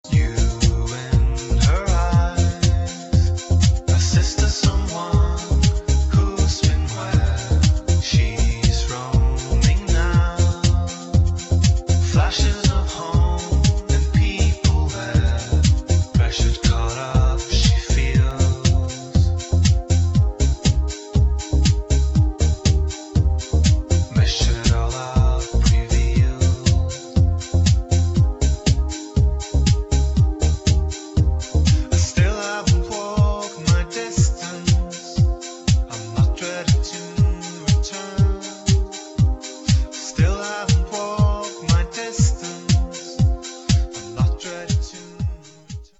[ HOUSE / ELECTRO POP ]